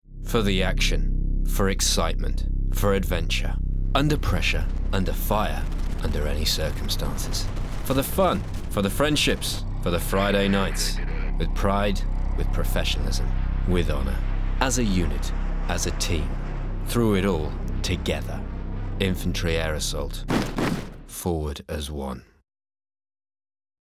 ::download:: Army Recruitment Commercial
Это действительно социальная агитка.